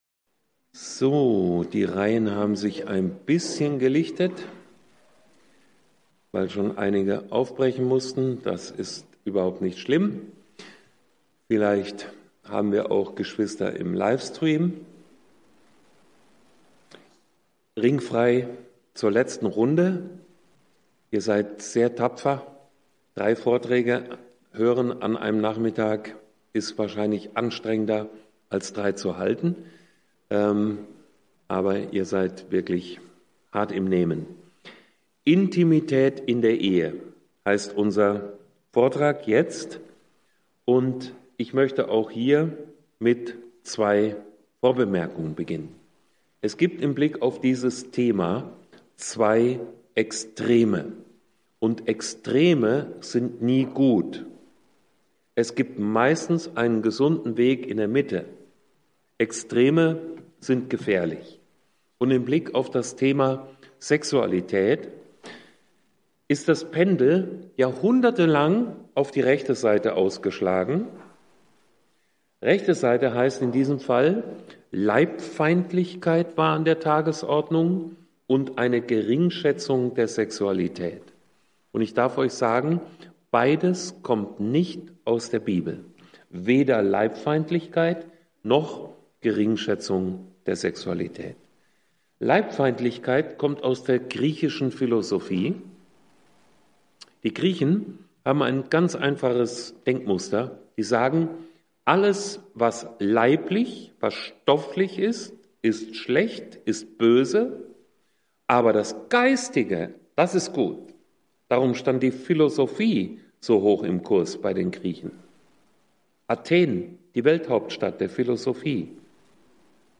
Vortragsreihe